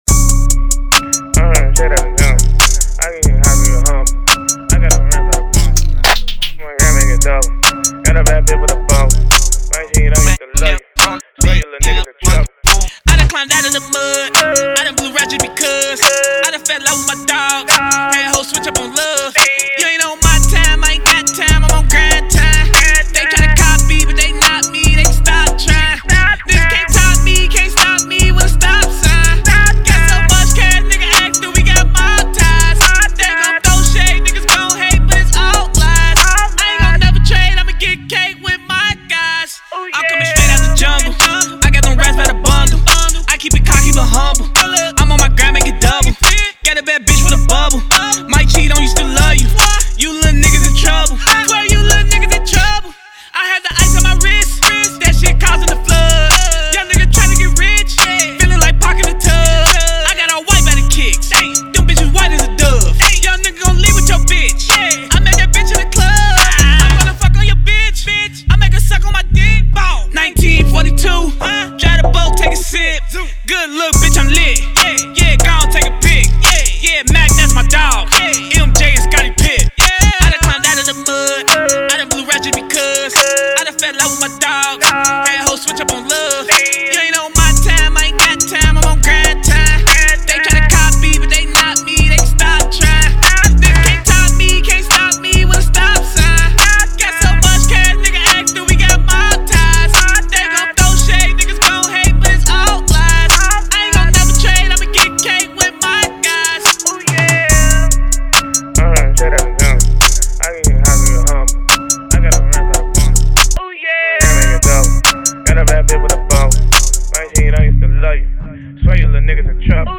Hiphop
Cincinnati Ohio Rapper & Recording Artist
Showing off his lyricism and natural bopping flow